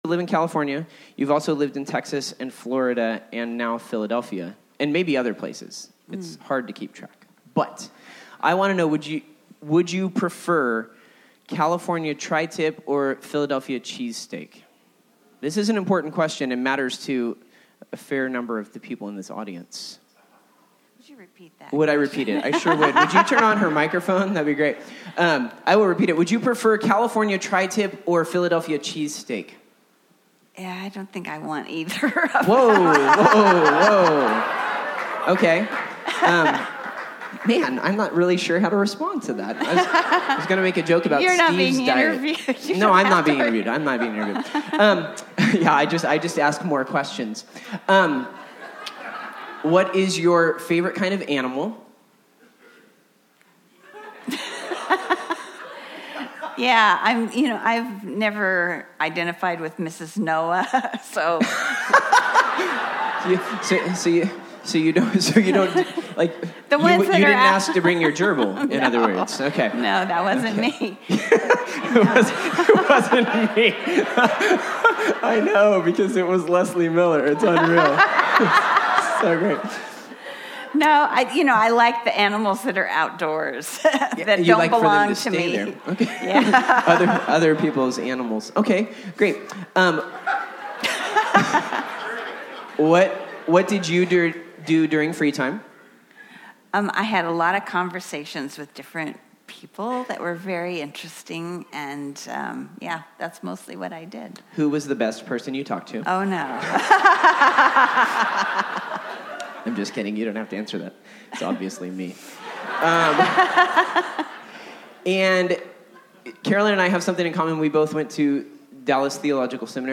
Church Retreat 2016 Saturday Night